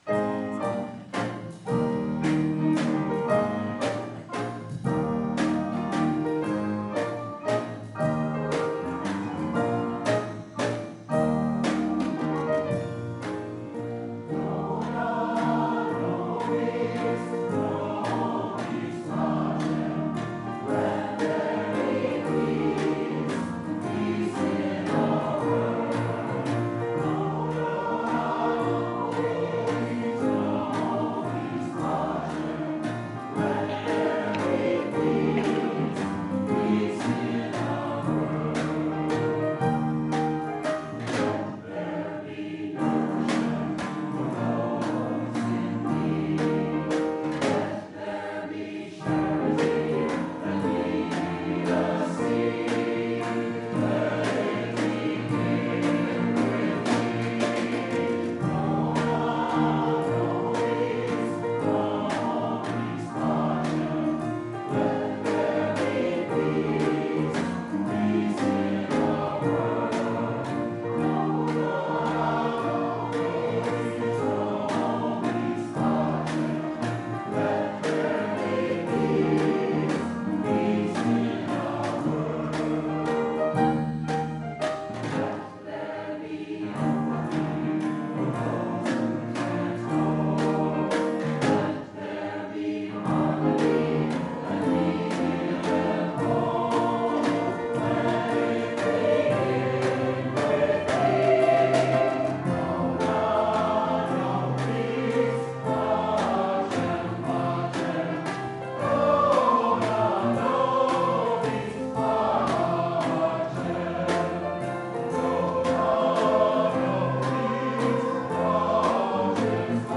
“Gospel Song of Peace” Choir Performance
Choir and Instrumental Music
Sung on the birthday of Dr. Martin Luther King Jr.